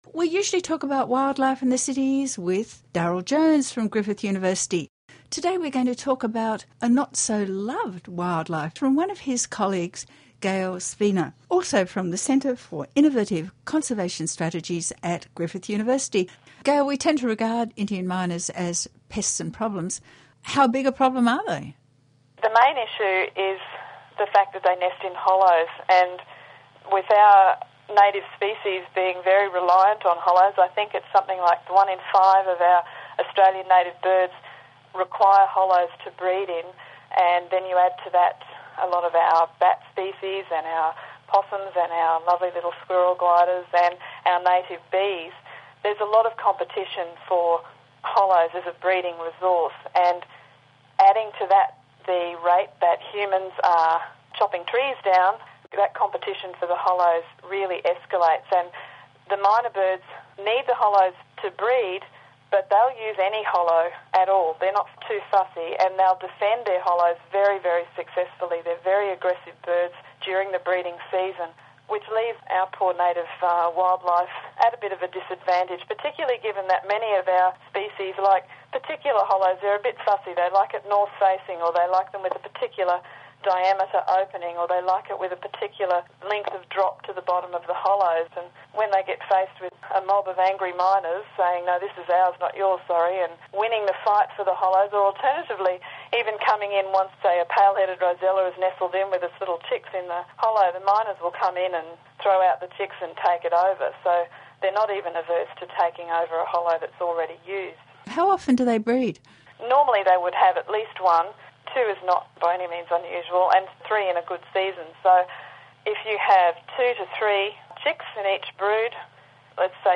Radio 107.2 FM interview